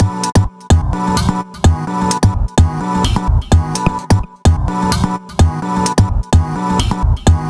Lis Pads Loop.wav